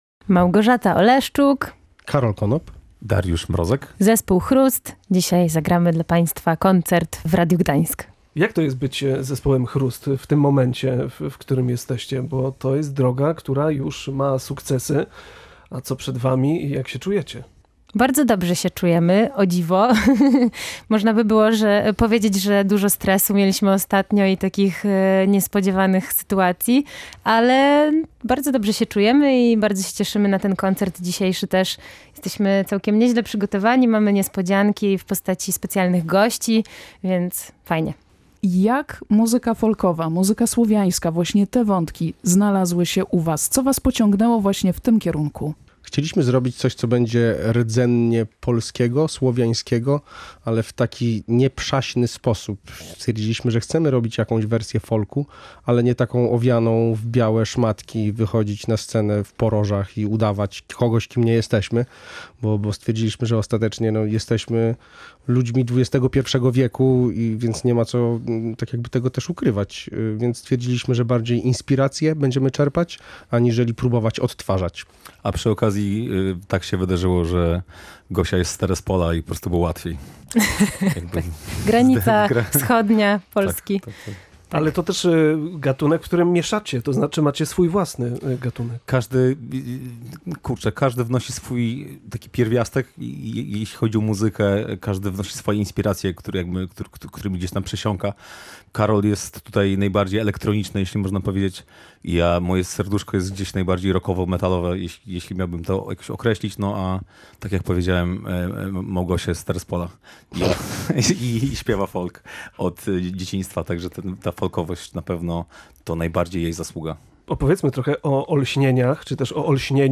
Prowadzący zapytali muzyków między innymi o to, skąd wziął się pomysł na słowiański folk. Rozmawiali też o plebiscycie O!Lśnienia i nagrodzie, który zespół otrzymał za wydany w zeszłym roku album „Przed Zmierzchem”.